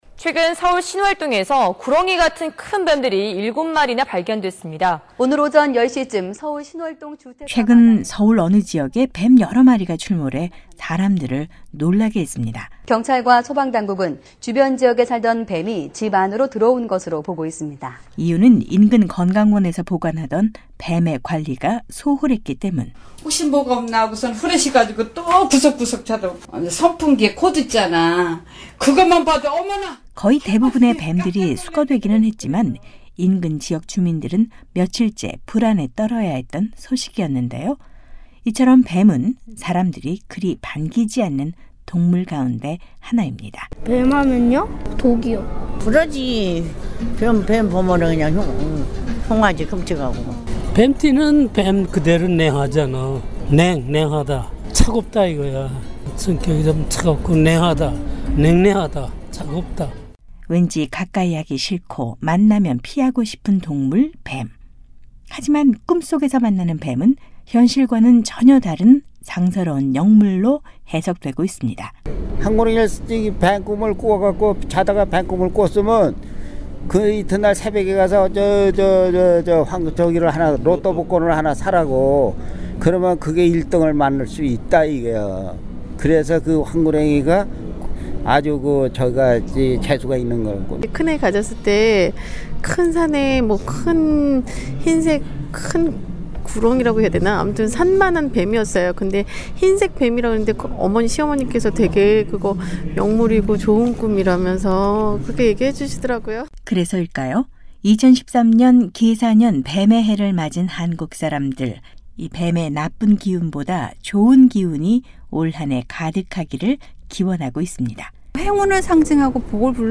한국사회의 이모저모를 전해드리는 ‘안녕하세요 서울입니다’ 오늘은 계사년 뱀의 해를 맞은 한국사람들의 목소리를 담았습니다.